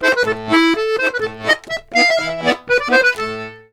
C120POLKA2-R.wav